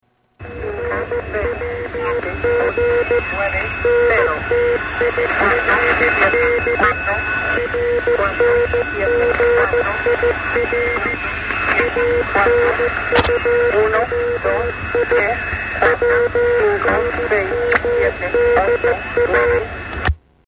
Spanish Counting Irdial
Category: Radio   Right: Personal
Tags: Radio Broadcast Secret Spy Broadcast NATO Number Station